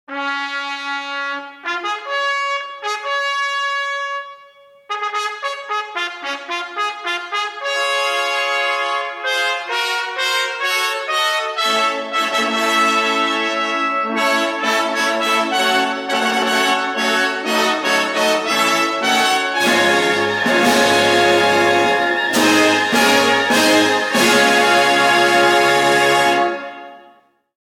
Фанфары